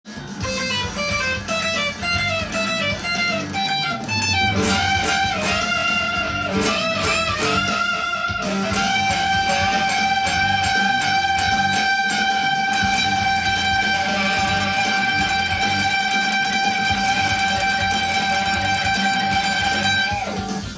Gミクソリディアンスケールを例にフレーズを作ってみました。
フレーズを使ったソロ